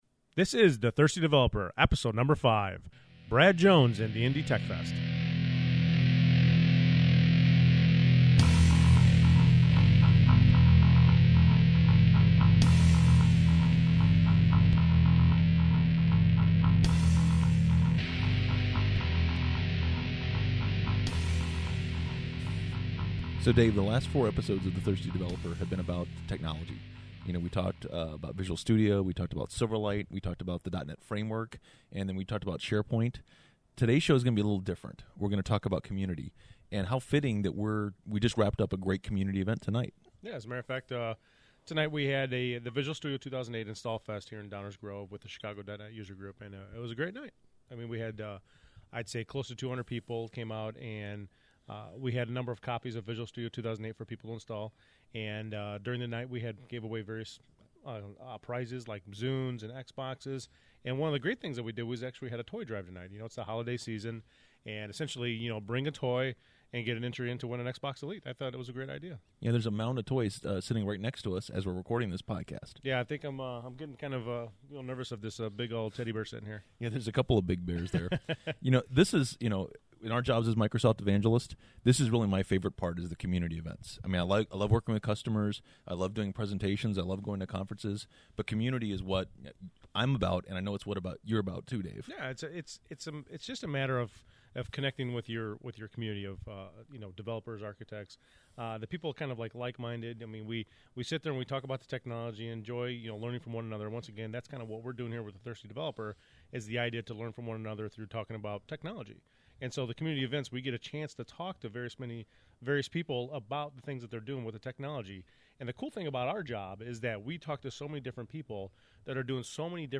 This is the fourth and last interview in our series of that were recorded at the IndyTechfest in Indianapolis, IN.